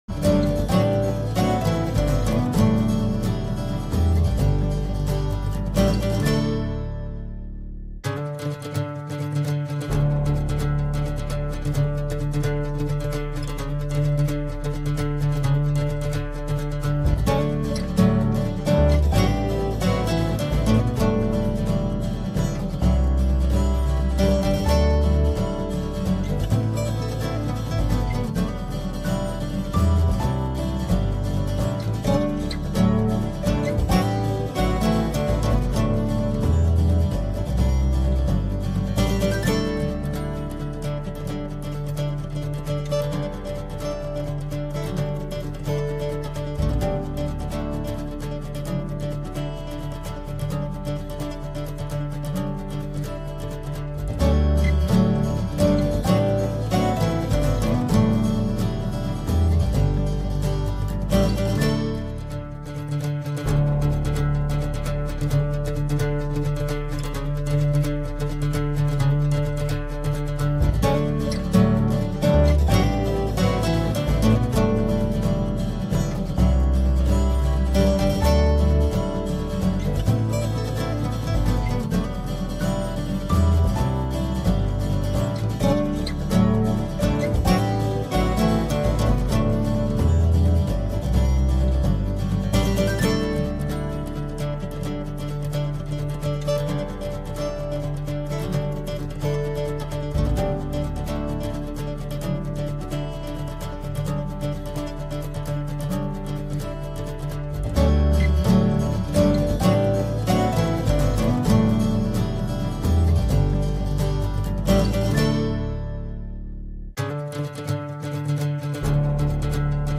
Un magistrado de la JEP y abogados expertos analizan la decisión de la JEP en la que sentenció a ocho años de sanciones al antiguo secretariado de las Farc, el alcance de la decisión y los retos como país para desarrollar las sanciones